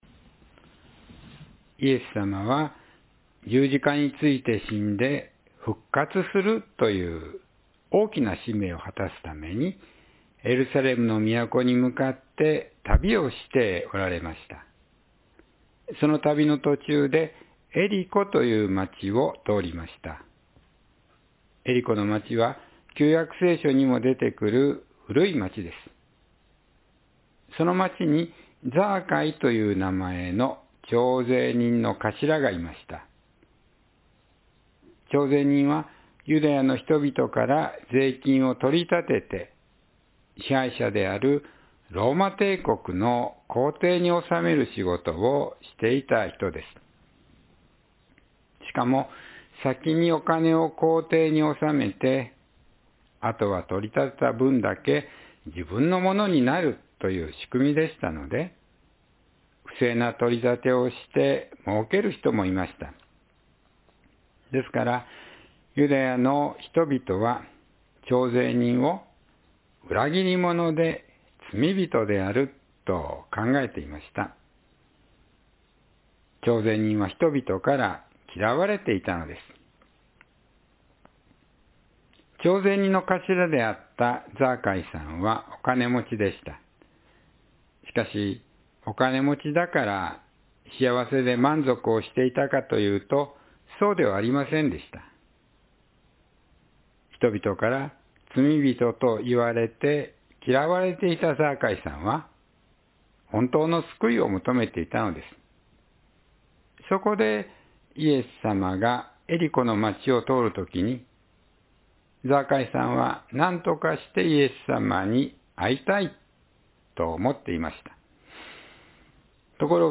救われたザアカイ（2026年3月1日・子ども説教）